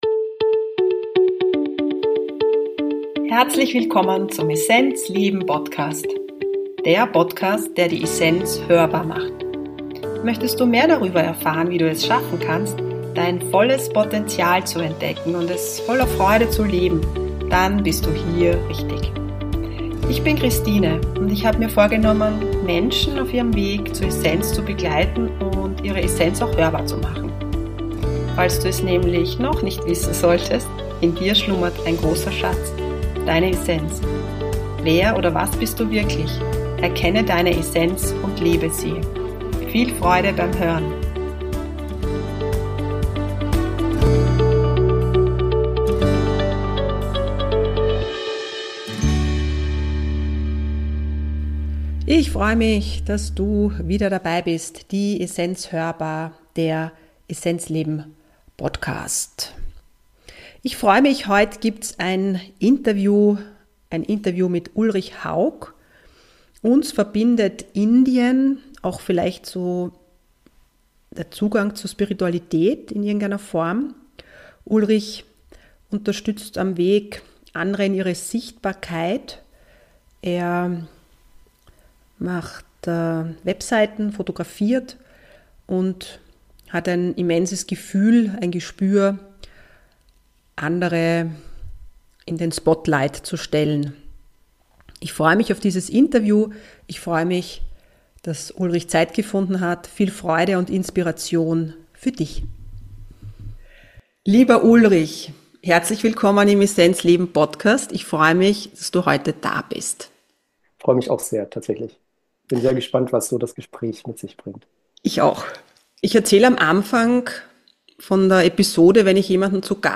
#125 Durch die Dunkelheit ins Licht - Interview